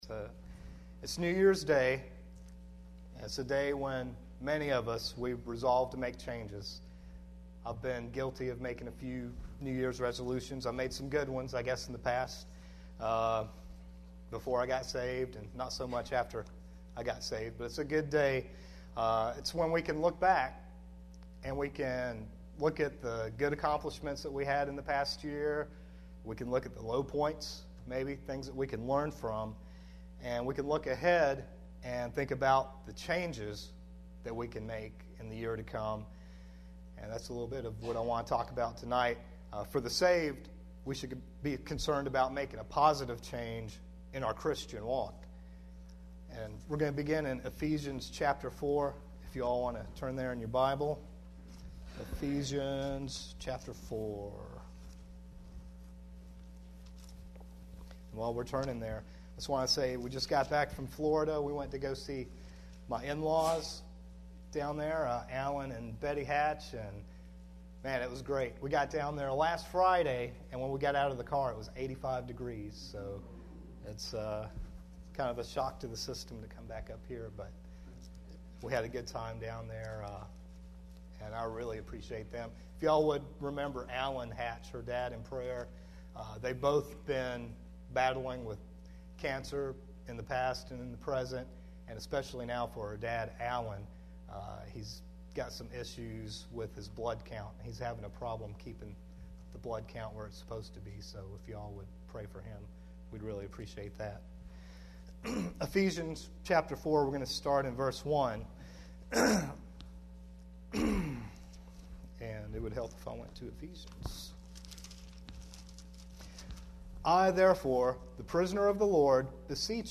January 1, 2012 PM Service Needed Changes for 2012 – Bible Baptist Church